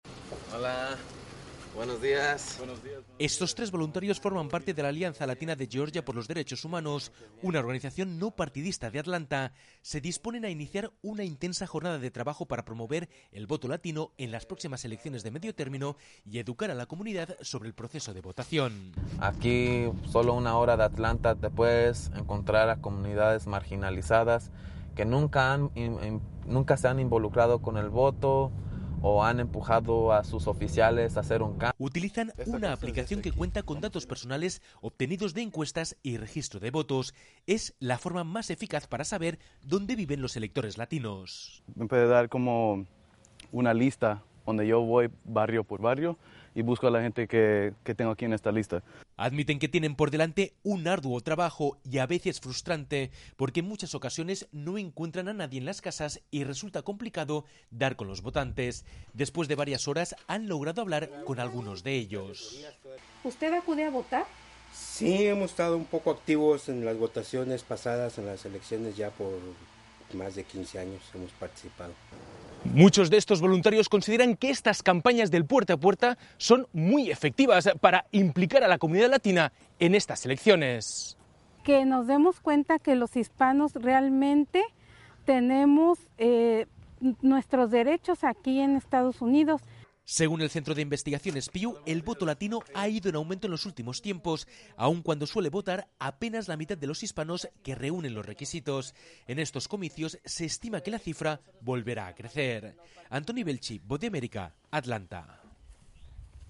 desde Atlanta, plantea el peso del voto latino en Georgia, un Estado clave que podría determinar la mayoría del Senado.